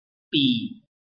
臺灣客語拼音學習網-客語聽讀拼-海陸腔-單韻母
拼音查詢：【海陸腔】bi ~請點選不同聲調拼音聽聽看!(例字漢字部分屬參考性質)